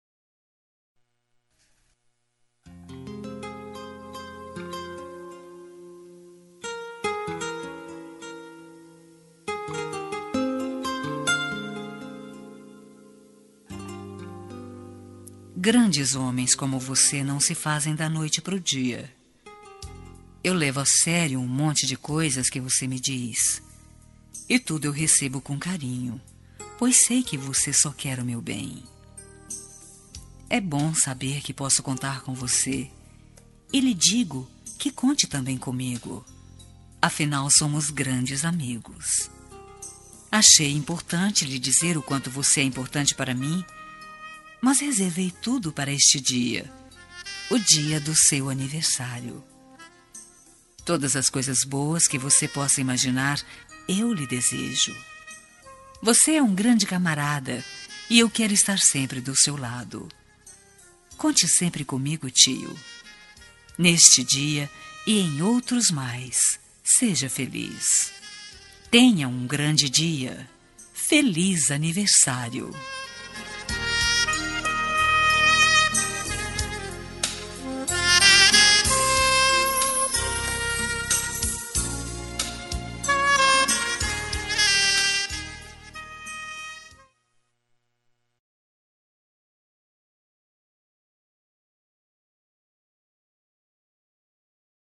Aniversário de Tio – Voz Feminina – Cód: 930
930-tio-fem.m4a